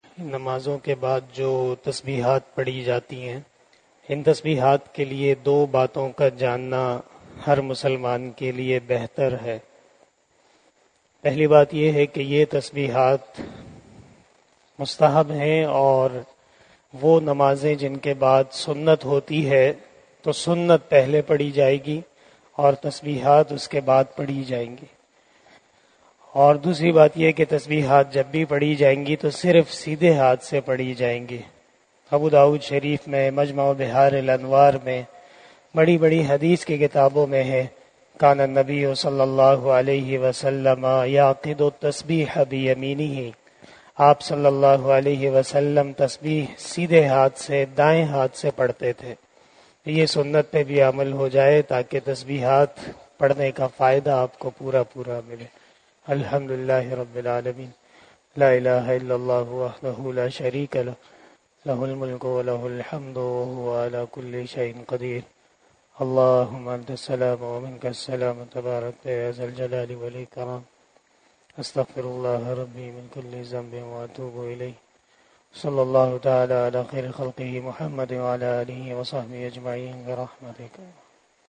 074 After Asar Namaz Bayan 18 Aug 2022 ( 19 Muharram 1444HJ) Thursday